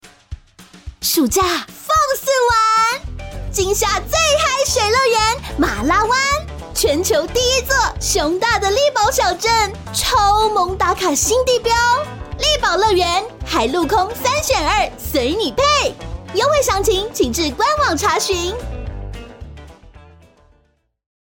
國語配音 女性配音員
她擅長國語配音，聲線既能俏皮活潑，也能沉靜溫柔，特別擅長詮釋少年少女角色與富有童趣的動畫情節。
• 聲線清新、富有生命力，擁有強烈親和力，適合各類動畫、遊戲角色